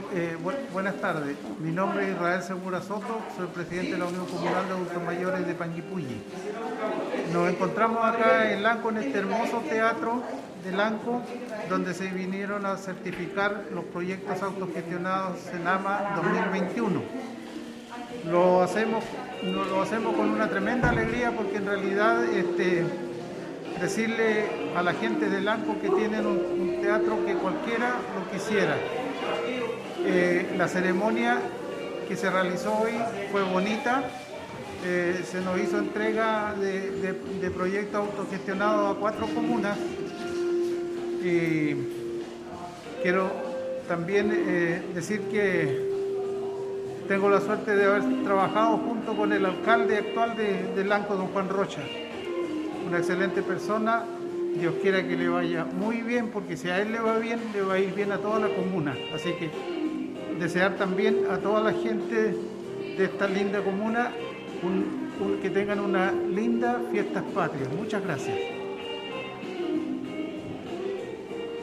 A la ceremonia realizada en el Teatro Galia de la comuna de Lanco, asistieron el alcalde de la comuna de Lanco, Juan Rocha Aguilera, la Seremi de Desarrollo Social y Familia Srta. Ann Hunter Gutiérrez, Patricio Ordóñez, Coordinador (S) de Servicio Nacional del Adulto Mayor, (SENAMA), el Senador Alfonso de Urresti, los diputados Marcos Ilabaca e Iván Flores, junto al Core, Ítalo Martínez y la concejala, Pamela Ramírez.